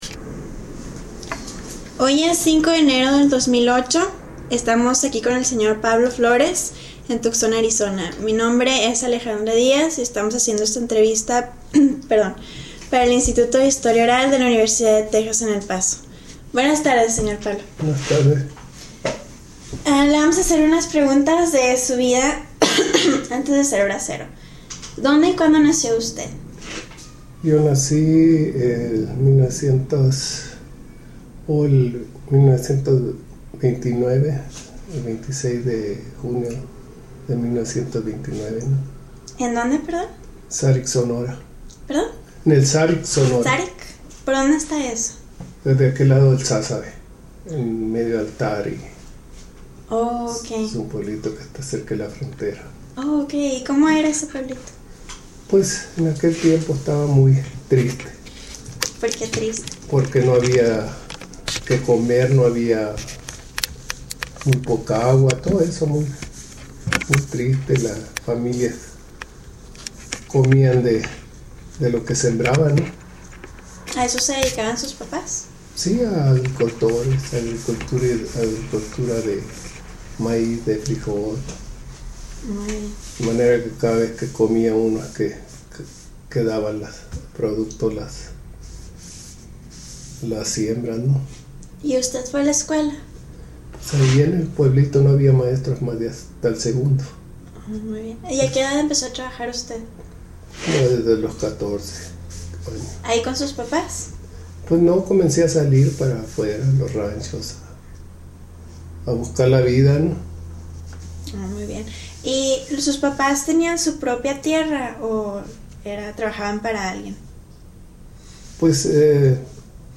Location Tucson, Arizona